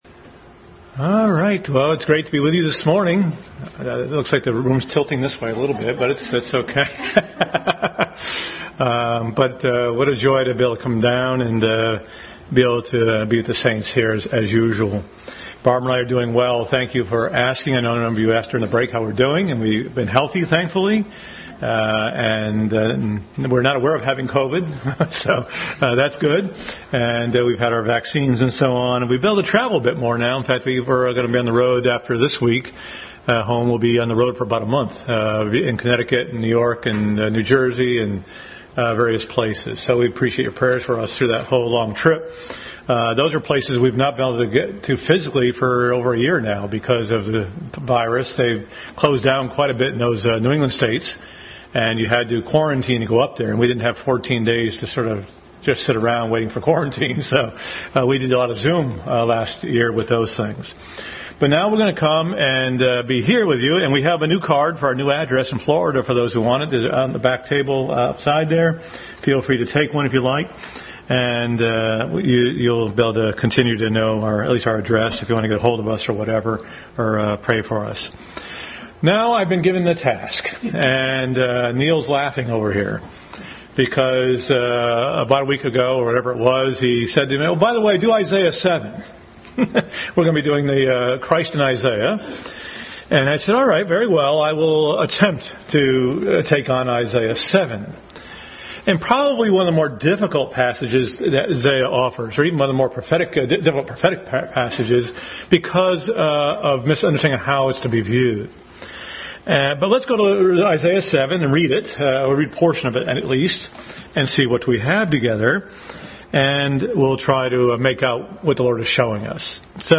Adult Sunday School Class continued study of Jesus in Isaiah.